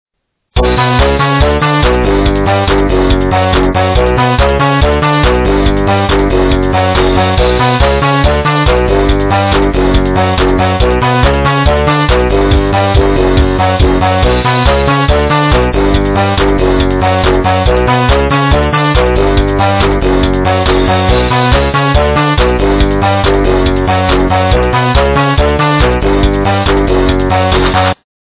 - рэп, техно